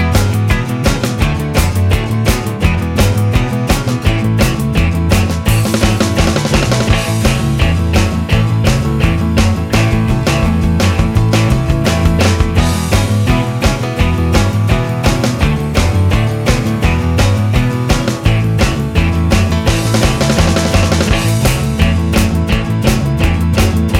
No Guitar Solo Pop (1980s) 2:47 Buy £1.50